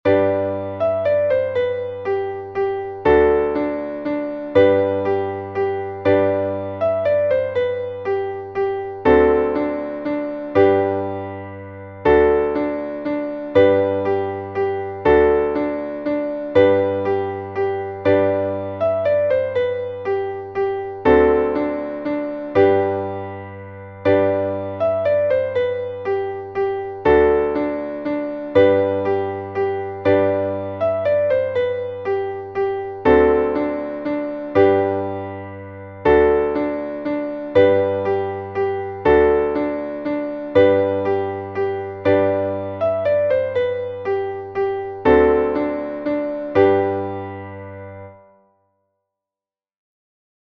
Traditionelles Kinderlied / Volkslied